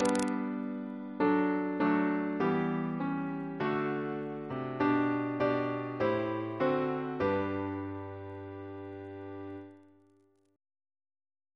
Single chant in G Composer: Arthur H. Brown (1830-1926) Reference psalters: ACB: 251